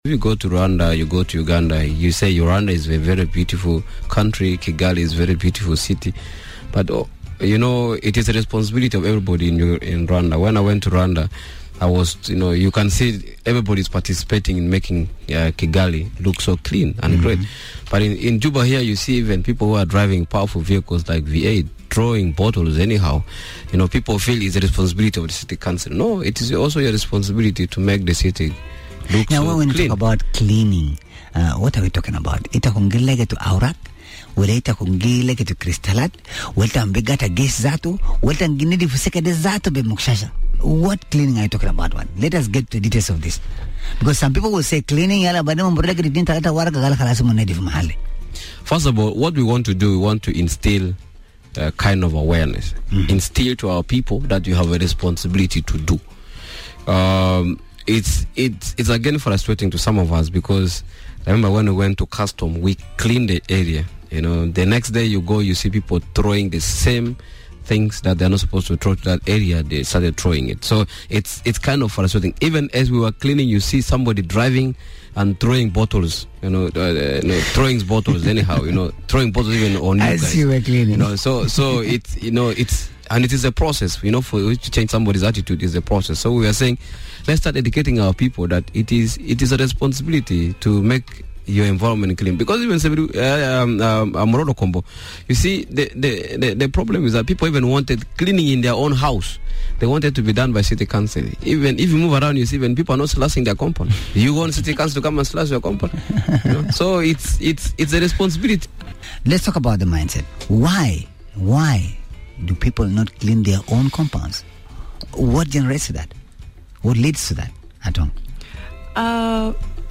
spoke to two of the campaigners